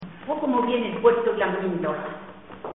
Materia / geográfico / evento: Refranes y proverbios Icono con lupa
Zafarraya (Granada) Icono con lupa
Secciones - Biblioteca de Voces - Cultura oral